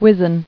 [wiz·en]